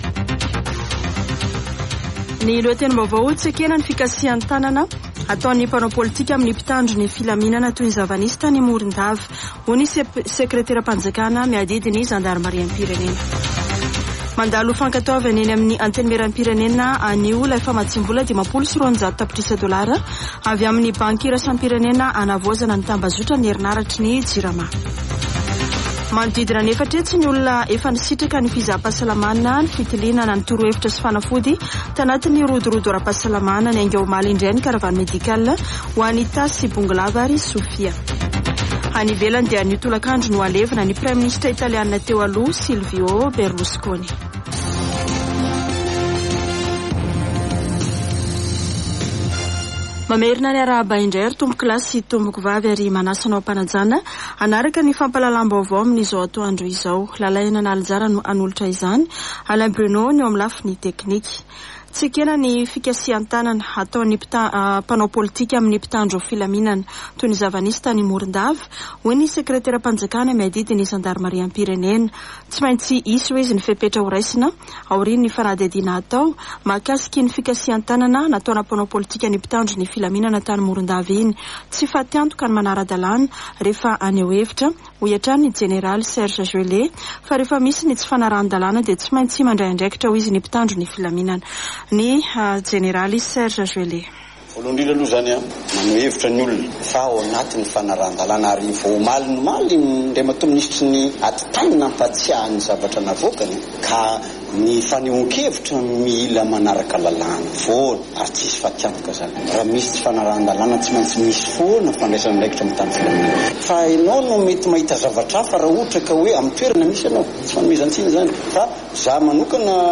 [Vaovao antoandro] Alarobia 14 jona 2023